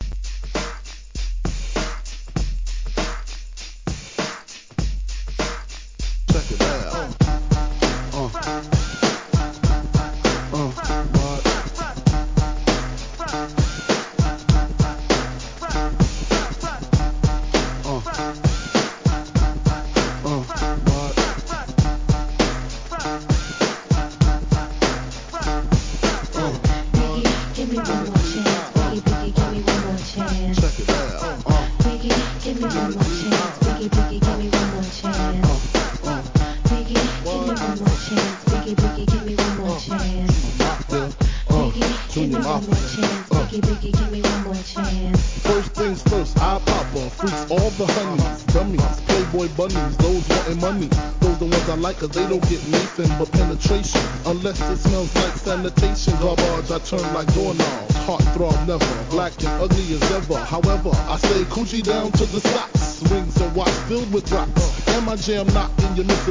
HIP HOP/R&B
(100 BPM)